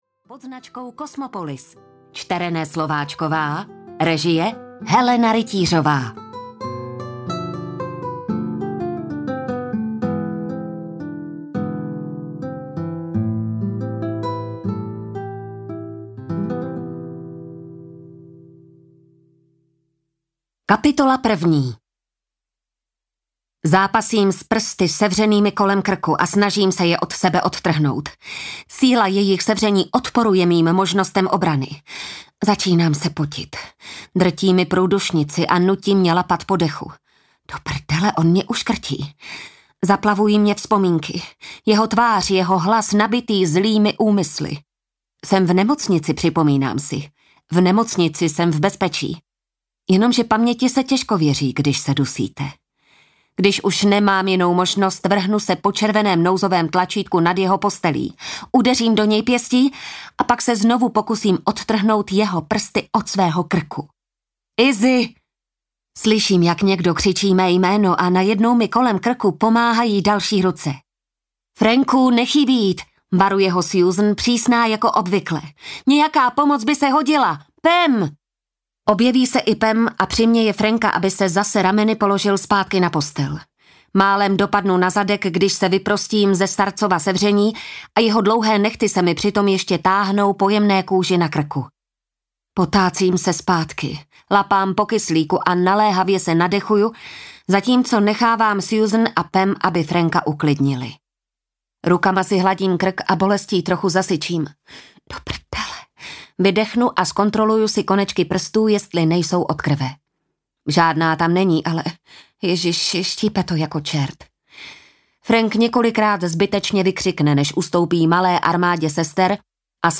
Hříšný gentleman audiokniha
Ukázka z knihy